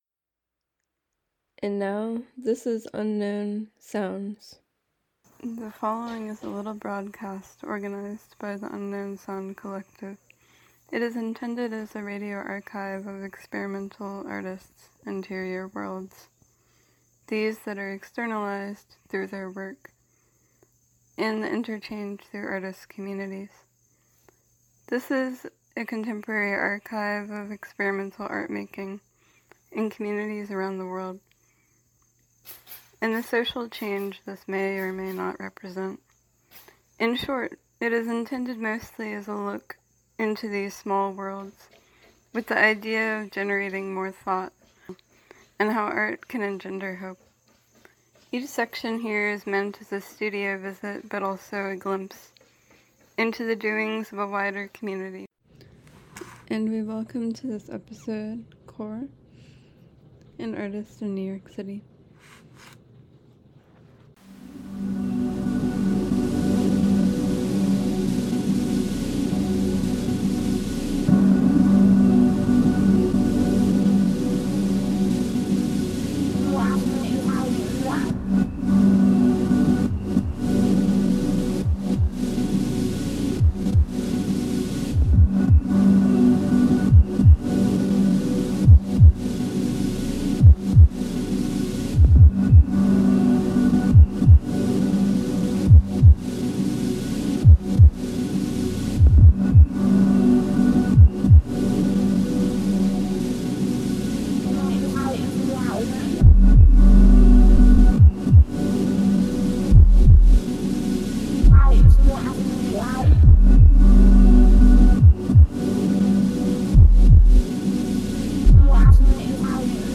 Each section here is meant as a studio visit, but also a glimpse into the doings of a wider community and the cultural, political repercussions of experimental sound and art-making.